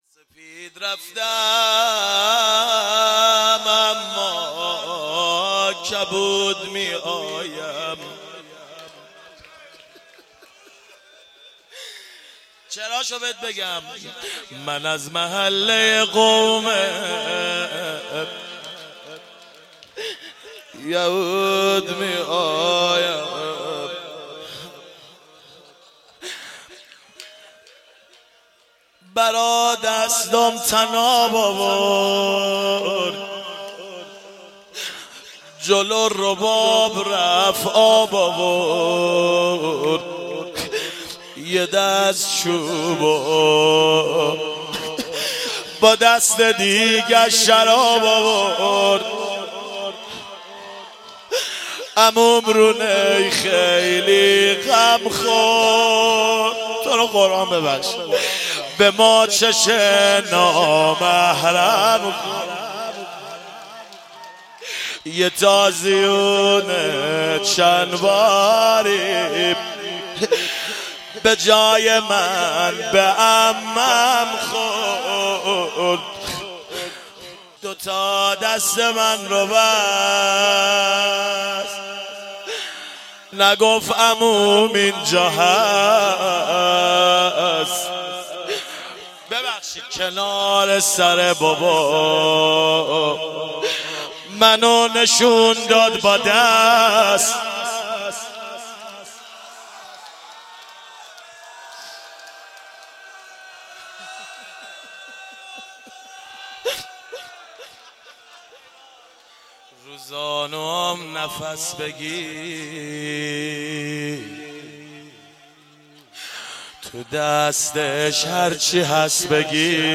روضه پایانی